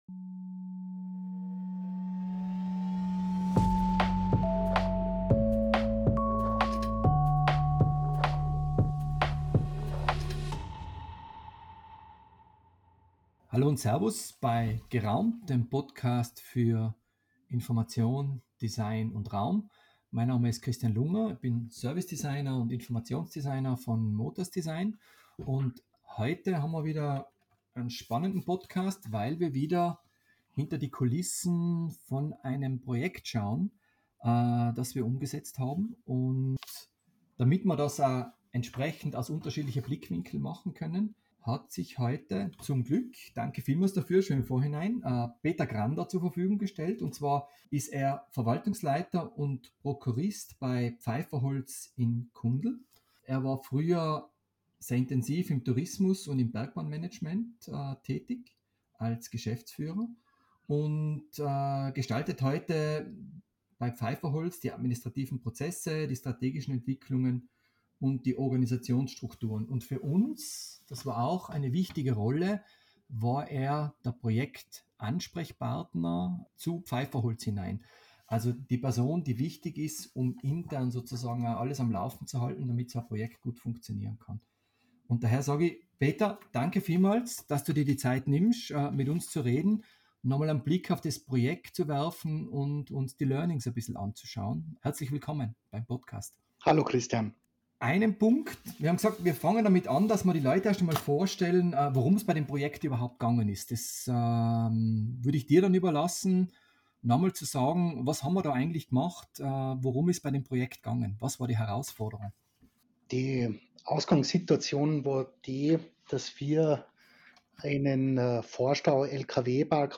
Informative Gespräche geben Einblick in die Welt der räumlichen Orientierung von Menschen in Gebäuden, Orten und im Naturraum.